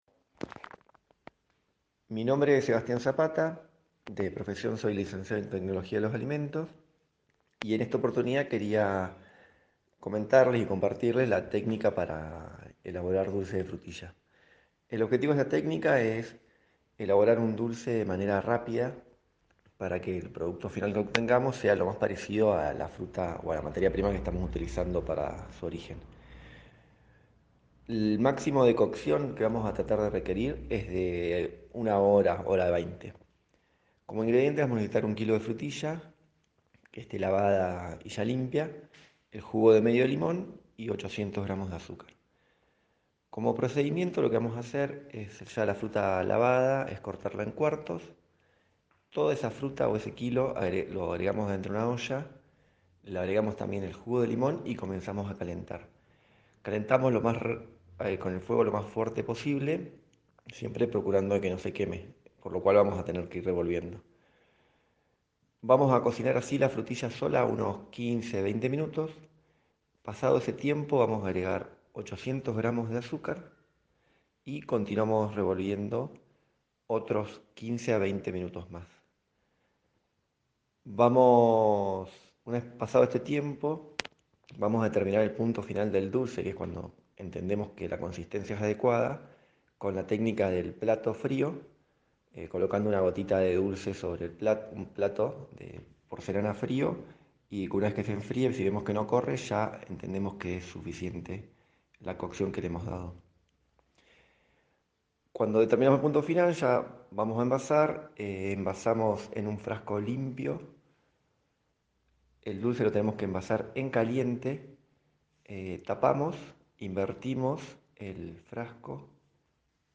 Una audio receta con ese toque bien casero: dulce de frutillas